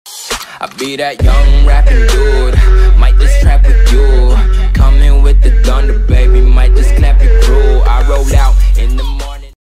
bester-donation-sound-vezos-donatoion-sound_gyoCear.mp3